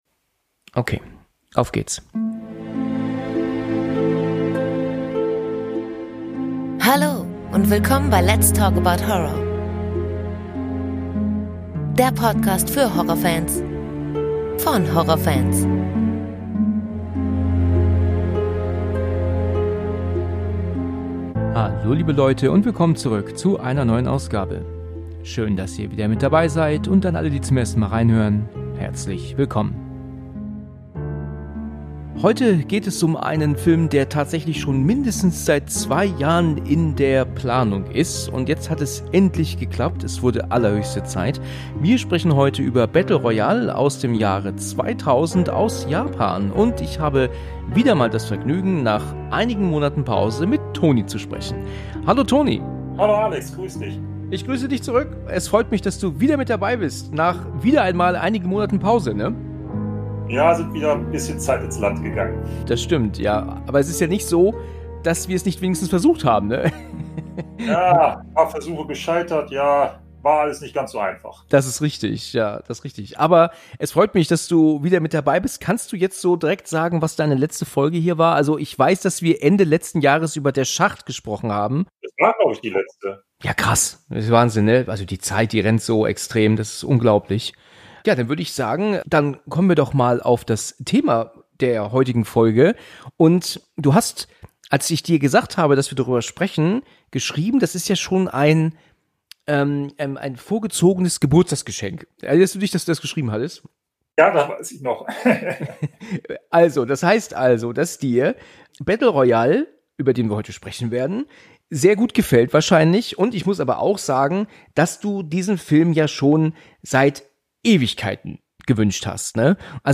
In diesem Podcast geht es um das coolste Genre überhaupt: Horror und Psychothriller! In jeder Folge bespreche ich mit wechselnden Gesprächspartnern einen guten (oder auch mal weniger guten) Film.